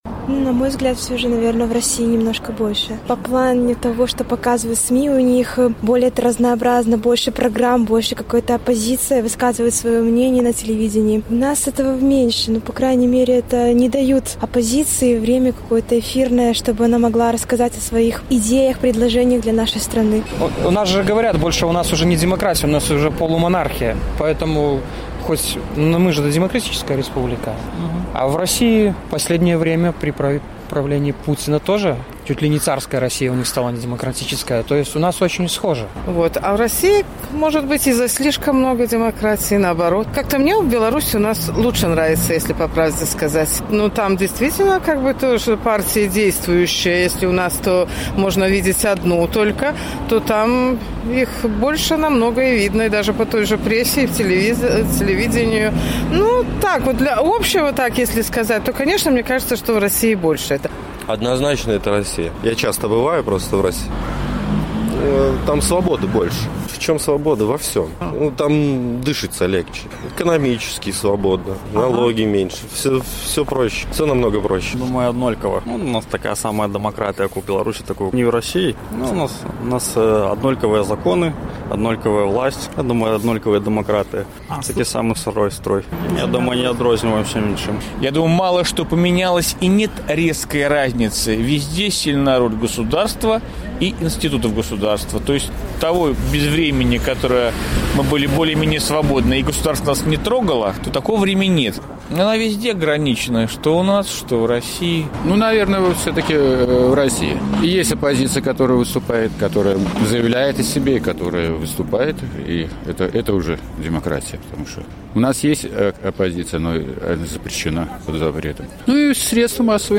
З такім пытаньнем наш карэспандэнт зьвяртаўся да гарадзенцаў.